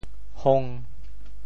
“黌”字用潮州话怎么说？
hong5.mp3